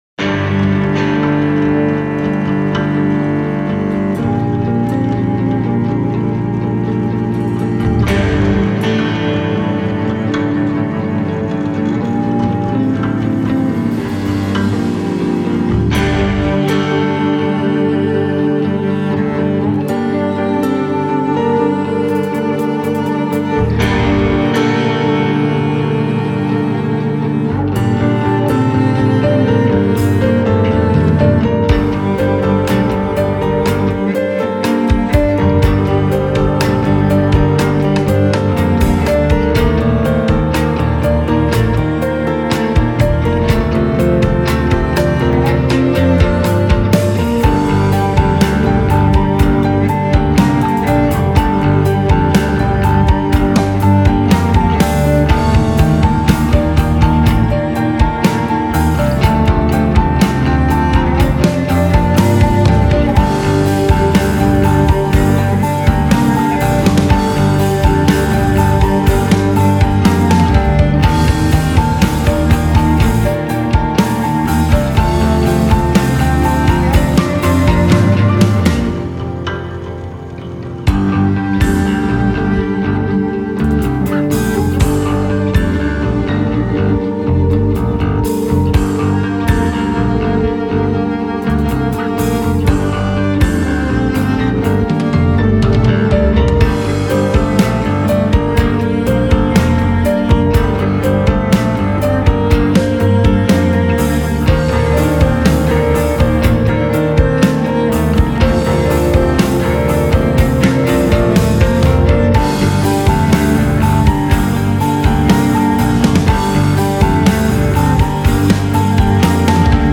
Атмосфэрно-роковый инструментал с виолончелью и пианиной
Обратился ко мне этакий камерно-роковый инструментальный ансамбль.
Тут все инструменты серединистые, на верхах кроме железа ничего и нет. Аранжировка та ещё, басист, пианино и виолончелистка хотят непременно солировать, причем постоянно.
(шумящие призвуки местами - это сэмплы скребков и почесывания виолончели, которые любы группе по какой-то причине) Вложения СМ.mp3 СМ.mp3 10,5 MB · Просмотры: 436